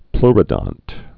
(plrə-dŏnt)